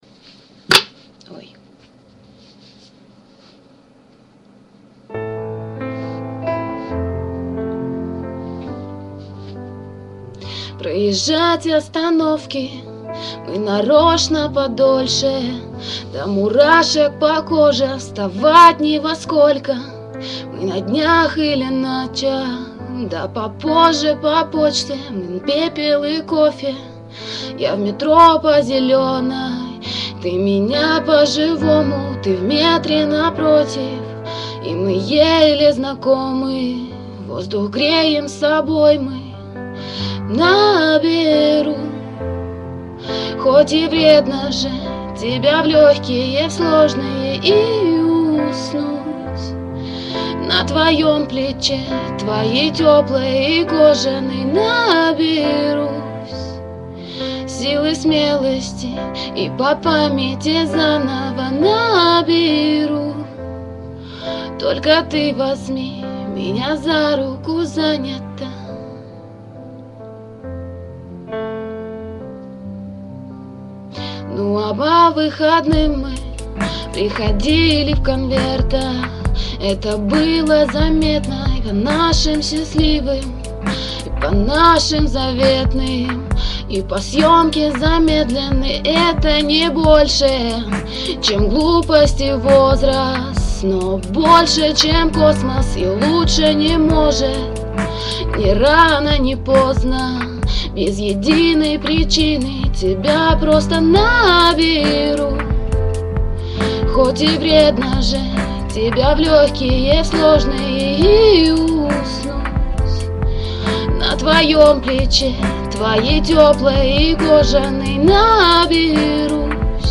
Кавер-версия